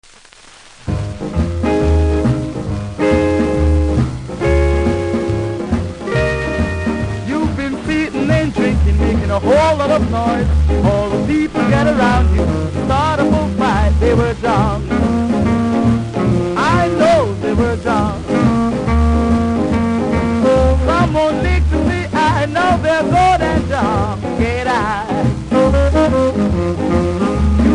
この盤は全てプレス起因のヒスノイズがありますので試聴で確認下さい。
ラべル・ダメージがあり見た目悪いですがキズによるノイズは少ないと思います。